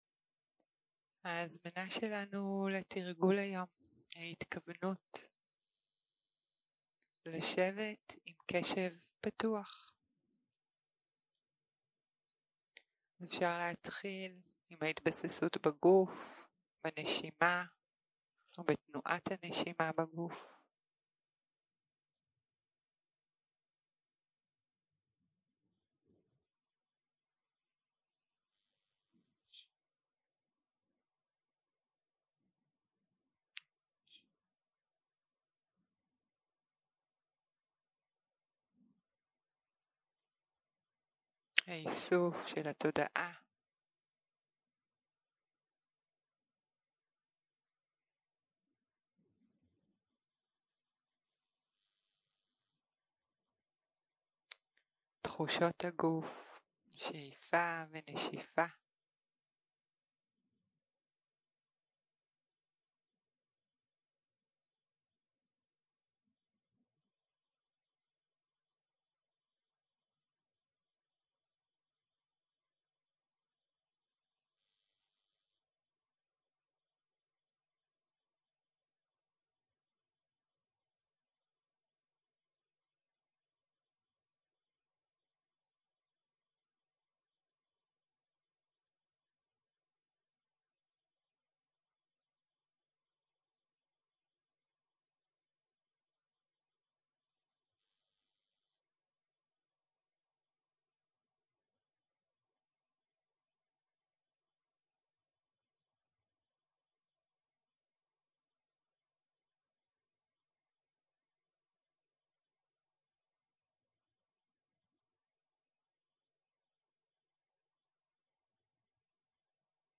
יום 5 - צהרים - מדיטציה מונחית - קשב פתוח - הקלטה 12